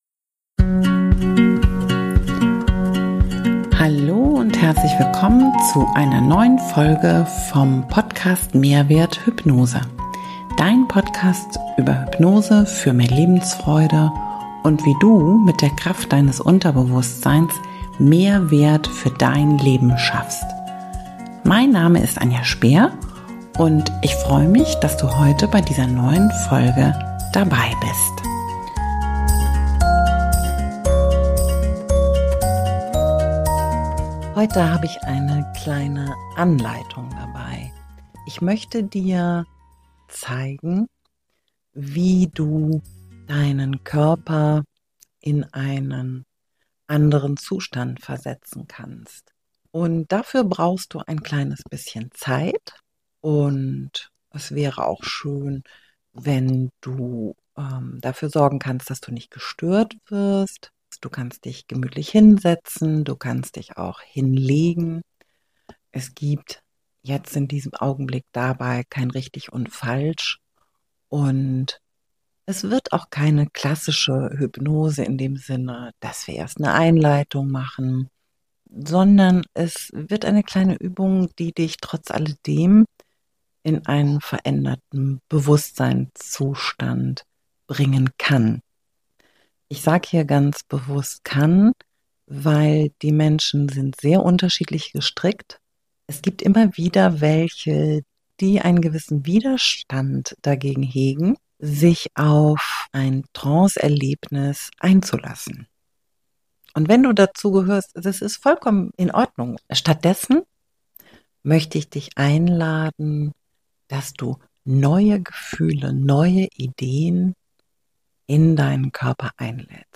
In dieser Folge lade ich dich zu einer sanften, geführten Übung ein – für mehr Leichtigkeit, Freude und Neugier in deinem Inneren.
Sie wurde frei, ohne Skript und ohne festen Plan eingesprochen – so, wie sie sich in diesem Moment zeigen wollte.